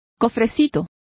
Also find out how cofrecito is pronounced correctly.